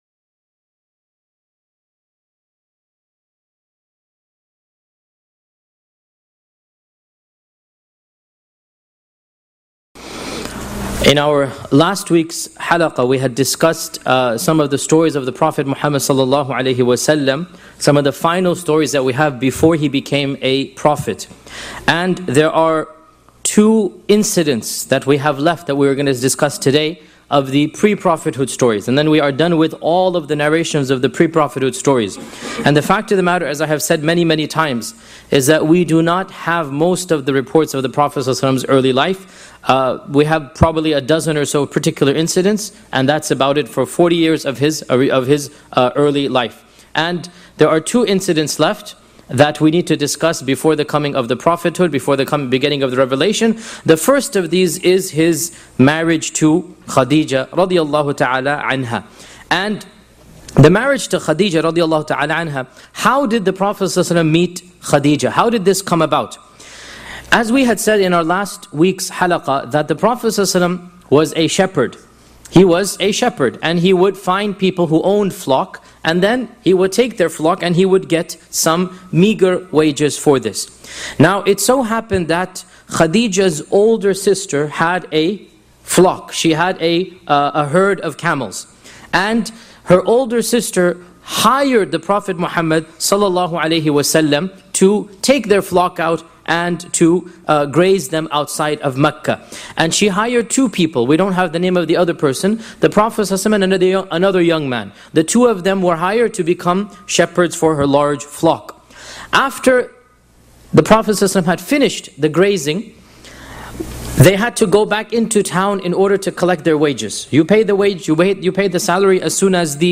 This Seerah lecture covers the early life of Prophet Muhammad (peace be upon him) before prophethood, focusing on his marriage to Khadijah (may Allah be pleased with her) and the remarkable signs that pointed to his future role as the final messenger of God.